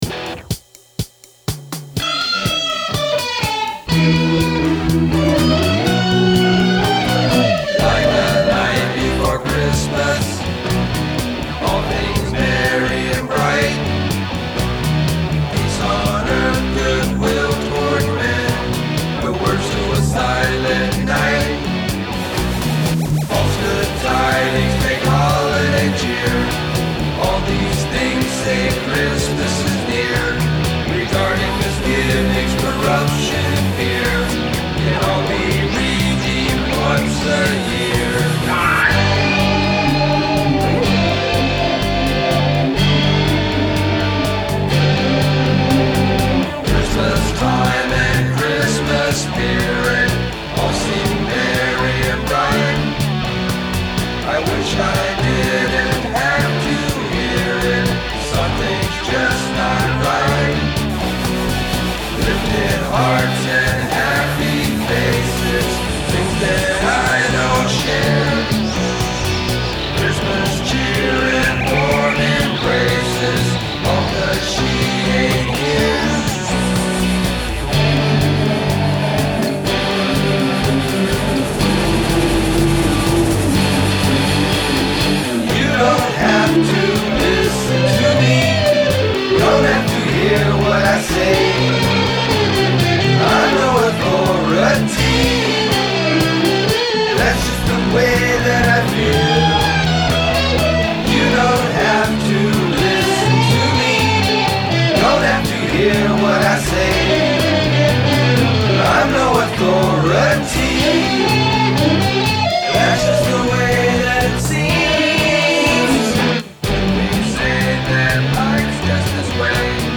Vocals, Bass, Organ, Steiner-Parker Synthesizer
Vocals, Electric Lead & Rhythm Guitars
Acoustic & Electric Guitars, Backing Vocals